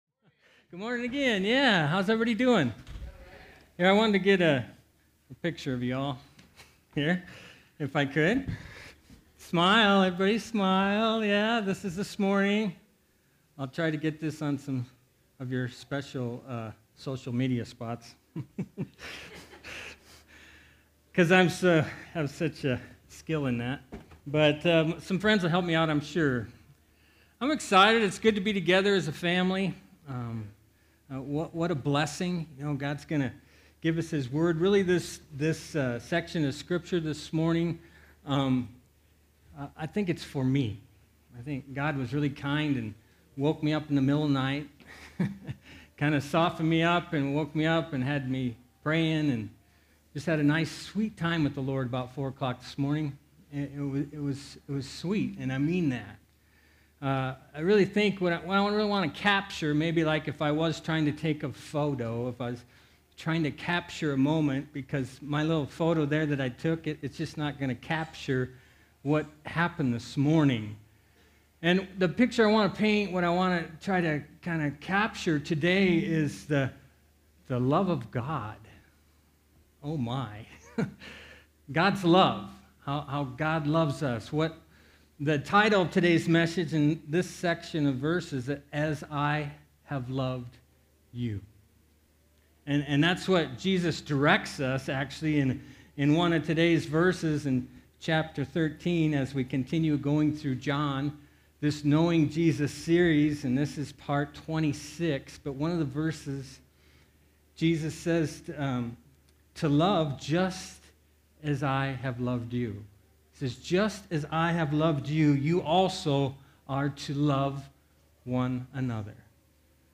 A message from the series "Your Love Remains."